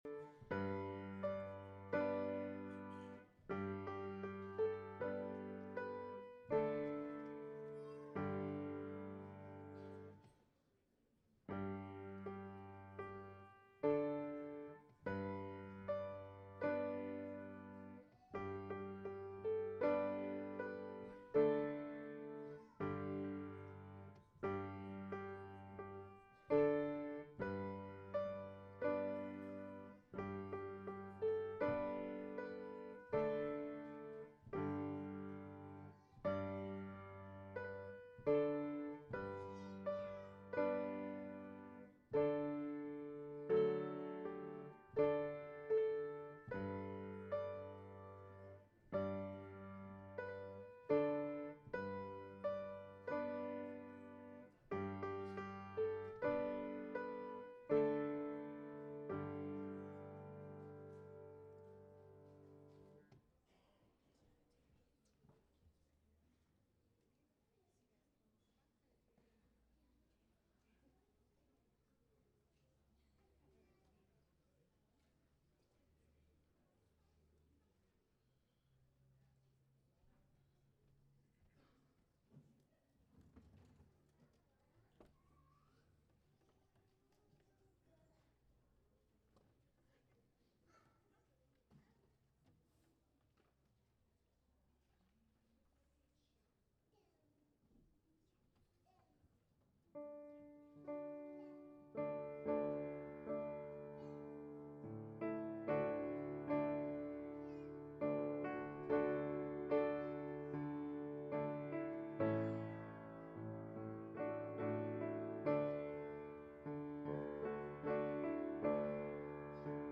Sunday School Easter Program